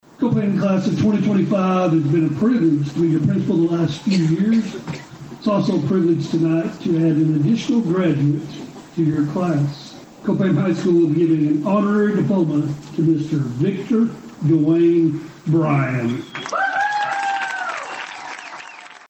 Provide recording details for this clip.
Copan High School Graduation 2025 The Copan High School graduation was broadcast live on 104.9 KRIG and was sponsored by Bartnet IP and OK Federal Credit Union.